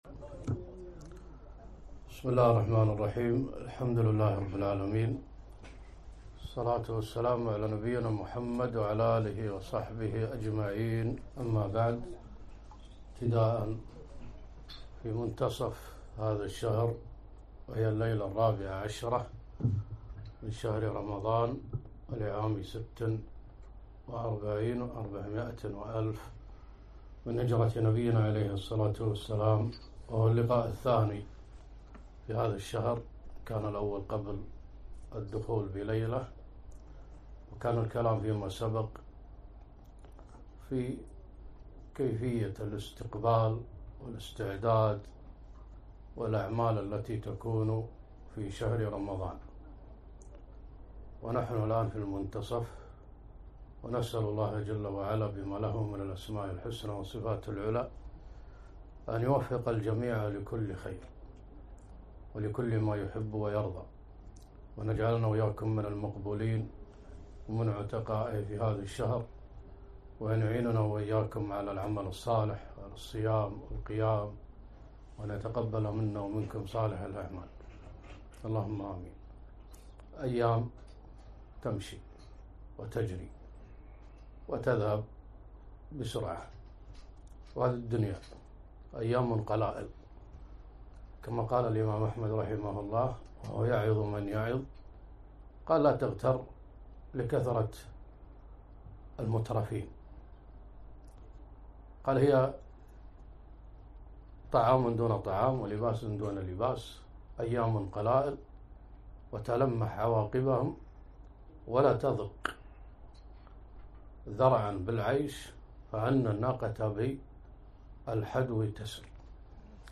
محاضرة - ومضى ثلث رمضان وهكذا العمر يمضي